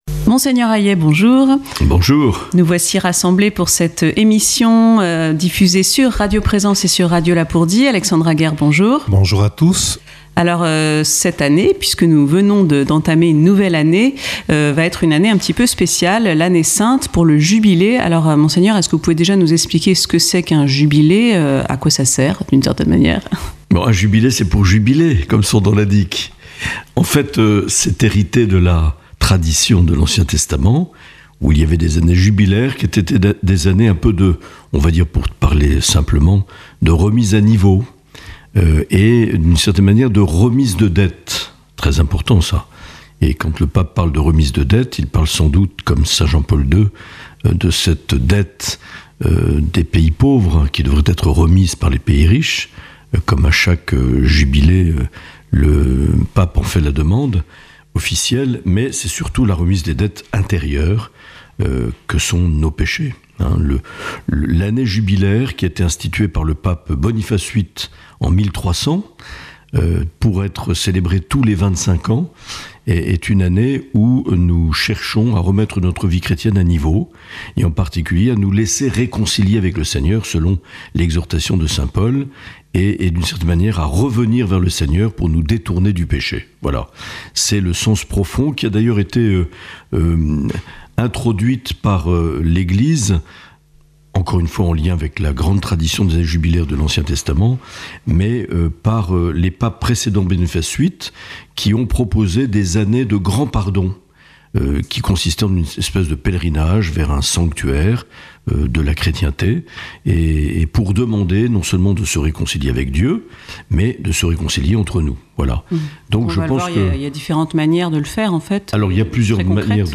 Entretien avec Mgr Marc Aillet, évêque de Bayonne, Lescar et Oloron
Dans cet entretien enregistré le 3 décembre 2024, Mgr Marc Aillet répond aux questions de Radio Lapurdi et de Radio Présence Lourdes Pyrénées.